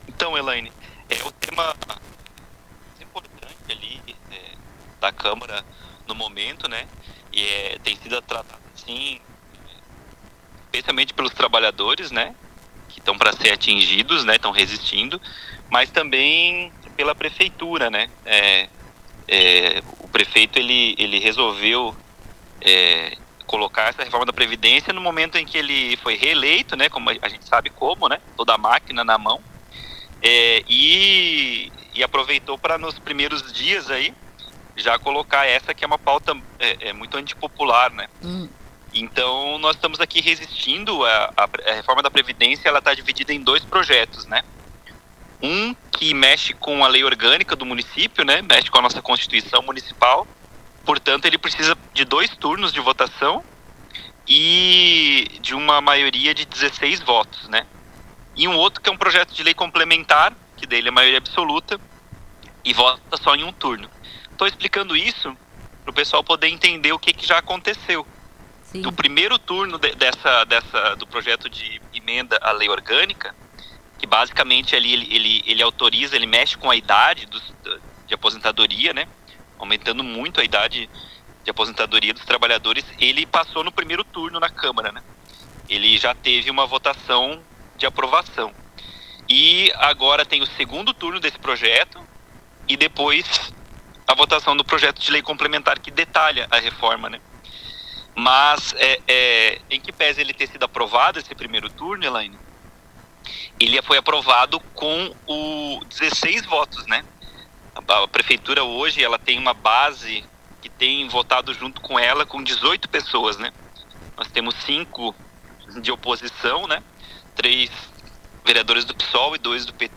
O vereador Bruno Ziliotto (PT) que é oriundo da luta dos municipários, fala sobre o tema e explica porque essa nova reforma da previdência municipal piora a vida não dos trabalhadores públicos, mas também da população.
Na entrevista Bruno também fala da situação da Comcap, desde 2012 sem concurso para trabalhadores, sendo sistematicamente destruída para acelerar a privatização.